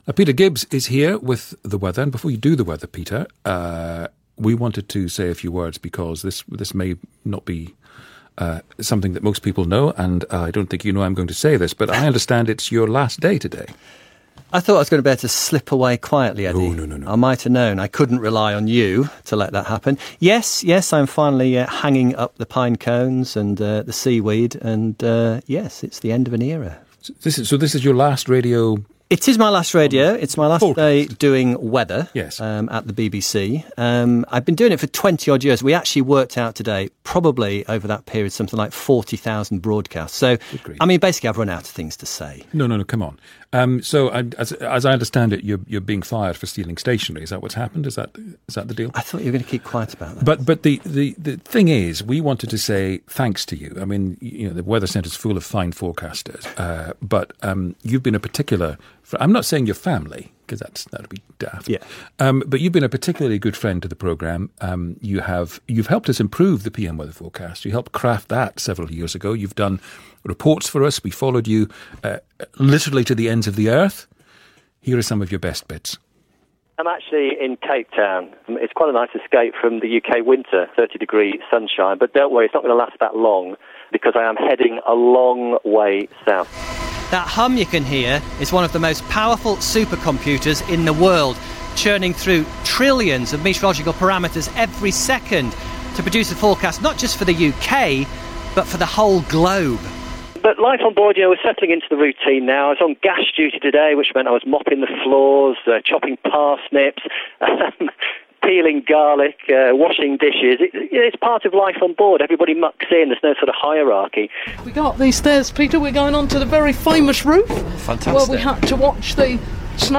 Peter Gibbs BBC weather forecaster for about 23 years made his final forecast on Radio 4's PM on 9 December 2016.